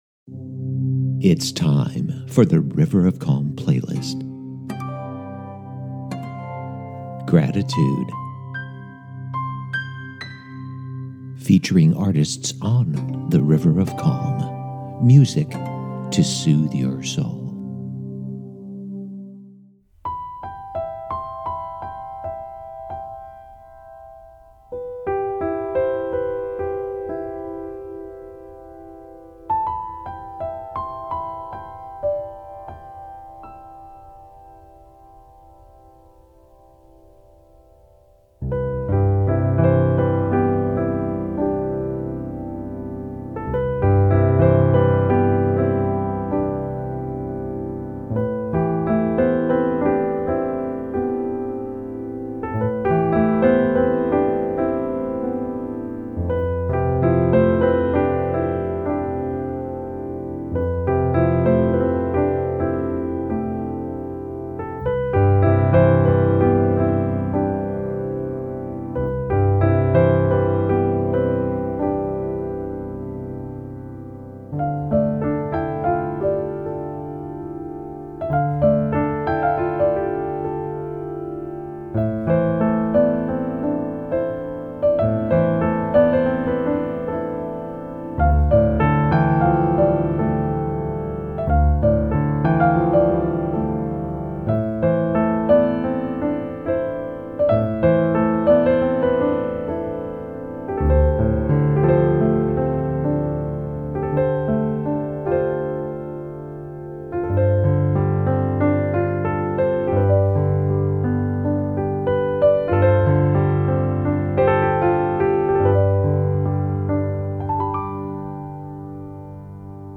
Music to Soothe Your Soul™!